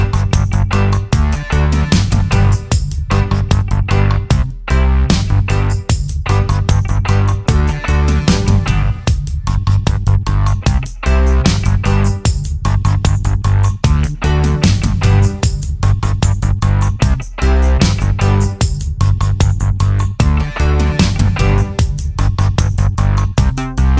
no Backing Vocals Reggae 3:21 Buy £1.50